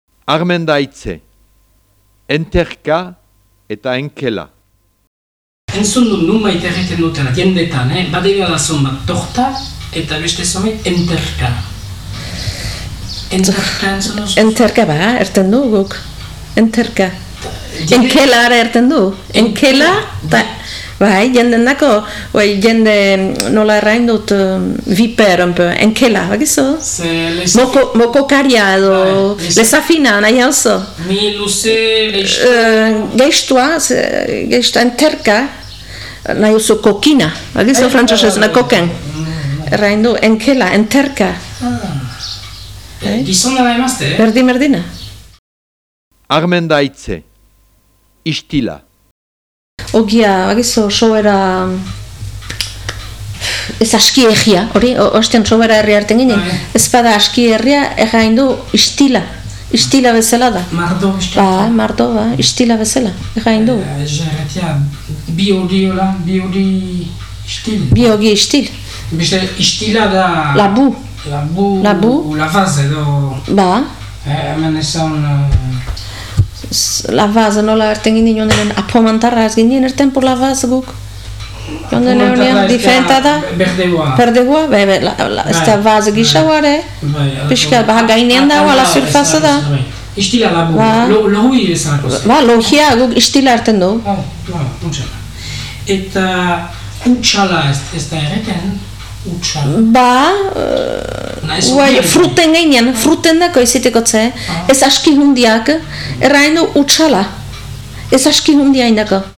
Lehenbiziko pasartean, 'enterka' hitza nola erabiltzen duen erraten du lekukoak. Bigarrenean, 'ixtil' eta 'hutsal' hitzak argitzen ditu.